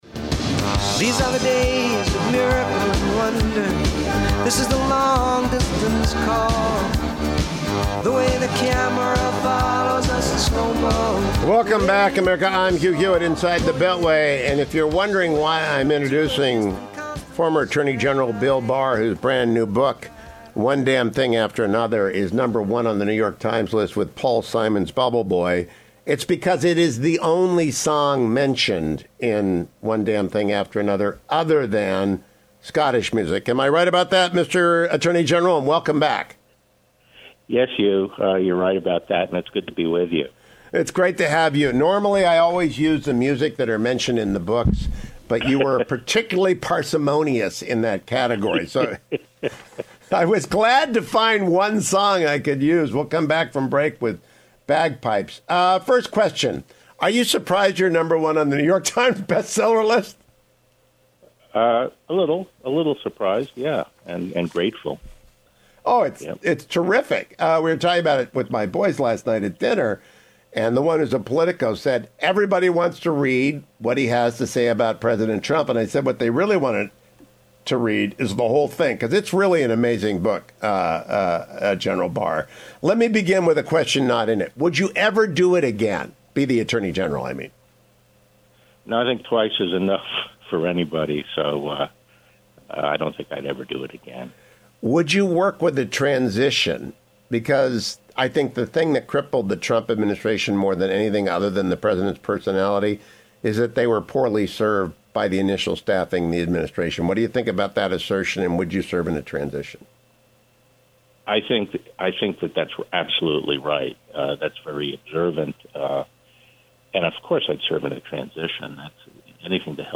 I spoke with AG Barr for 90 minutes and could easily have gone twice as long but you need to read the book, not summaries or reviews: Audio: 03-21hhs-barr Transcript: HH: And if you’re wondering why I’m introducing former Attorney General Bill Barr, whose brand-new book, One Damn Thing After Another, is number one on the New York Times list with Paul Simon’s Bubble Boy, it’s because it is the only song mentioned in One Damn Thing After Another other than Scottish music.
03-21-Bill-Barr-TheInterview-podcast.mp3